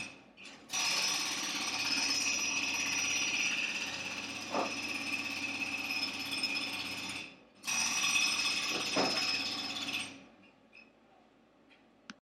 Presslufthammer mit mir.
pressluft.mp3.mp3